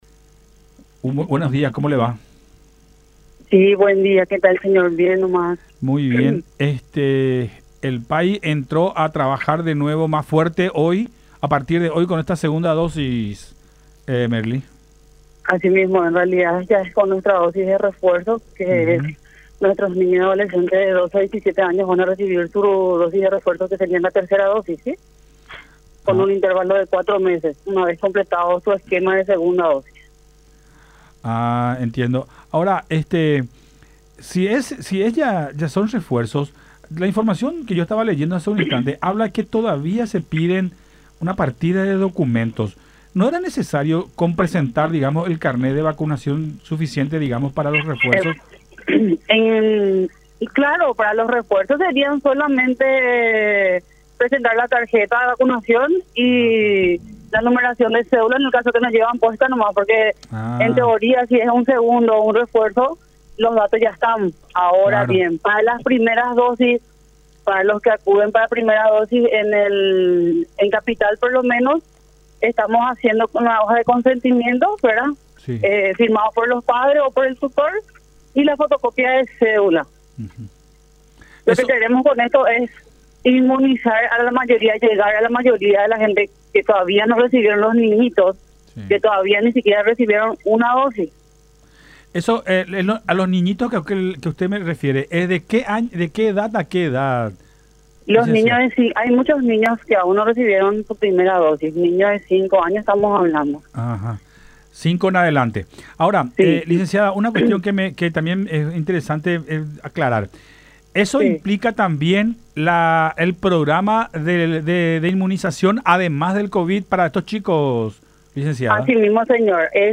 en diálogo con Nuestra Mañana por Unión TV